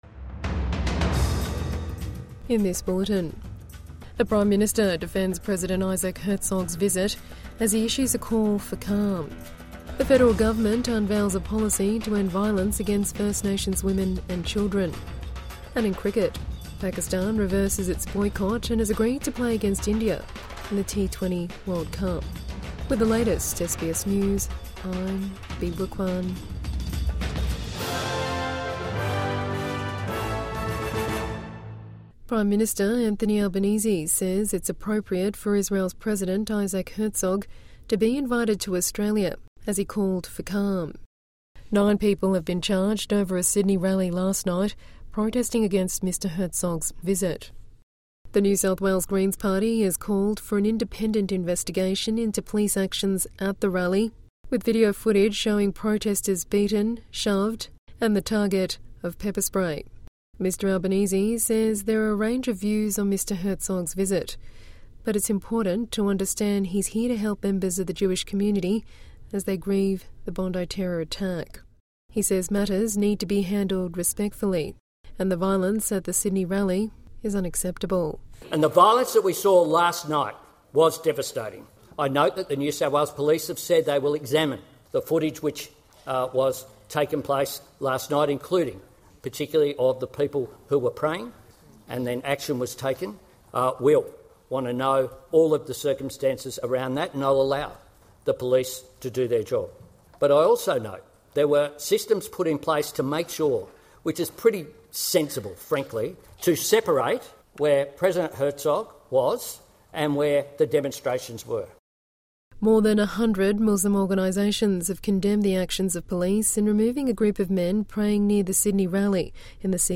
Listen to Australian and world news and follow trending topics with SBS News Podcasts.